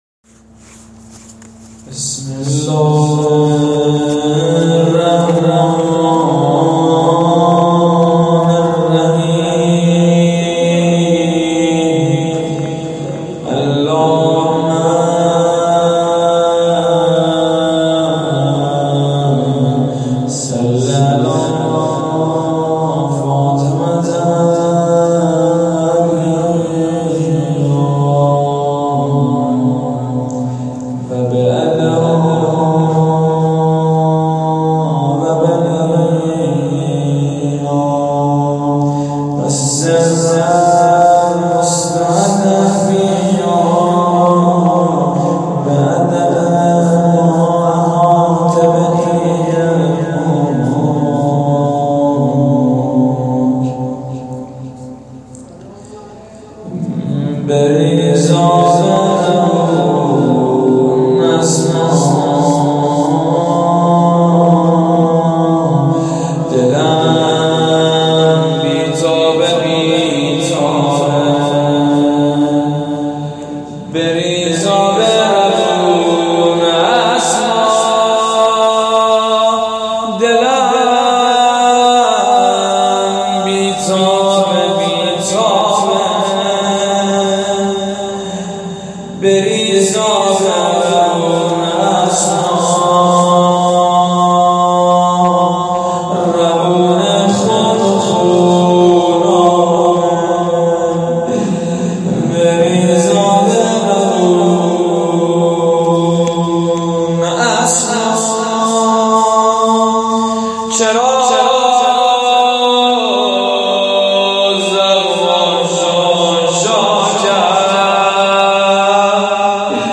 روضه حضرت زهرا (س)در مسجد جواد الائمه (ع)